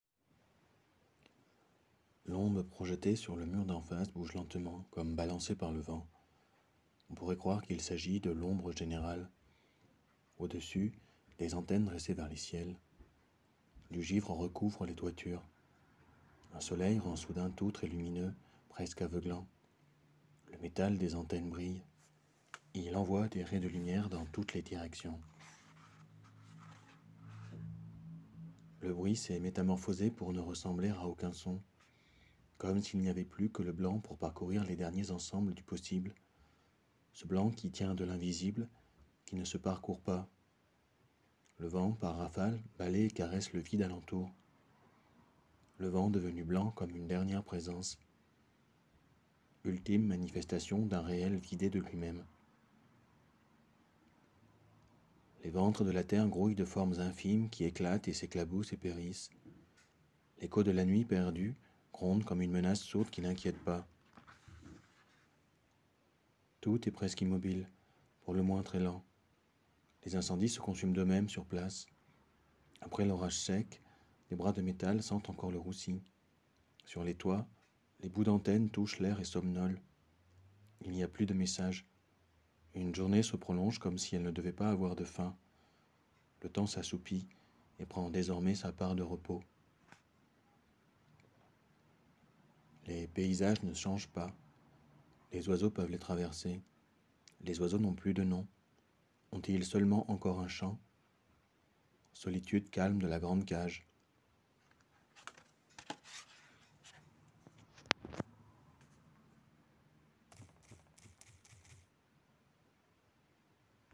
Extraits du recueil lus par l’auteur :